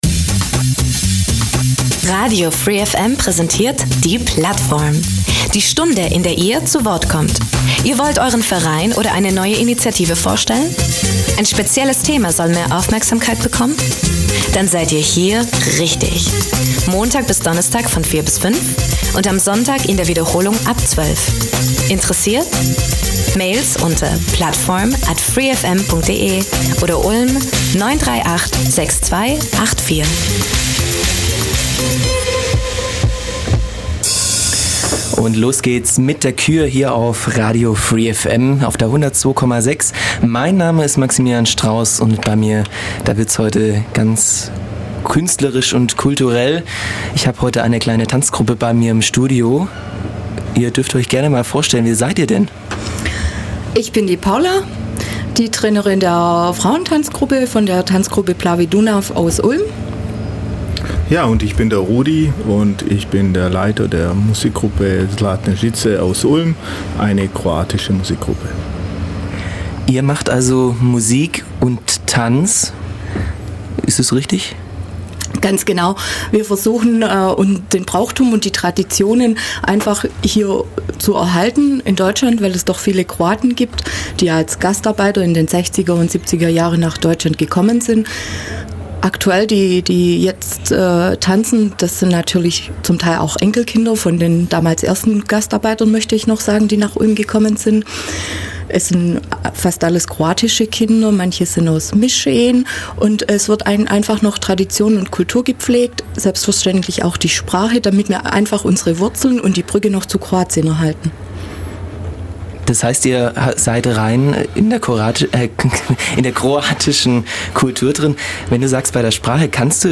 Gespielte - gesungene und getanzte Tradition.